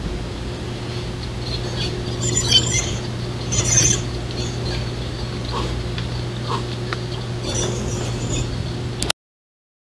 Еще звуки пищания мышей